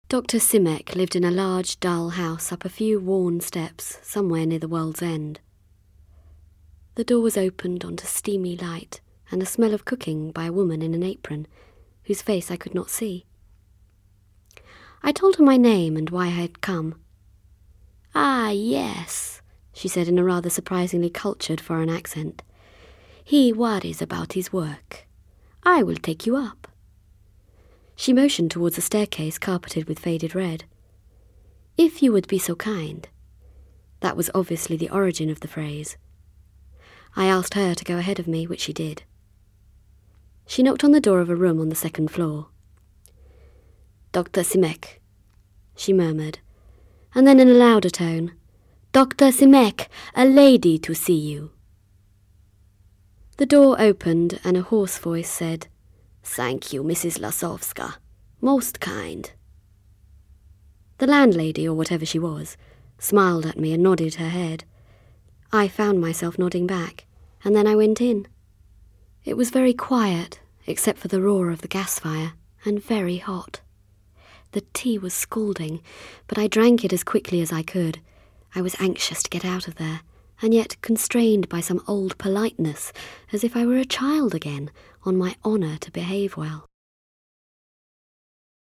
Radio 4 narrative read. Innocent, Dreamy, Subtle https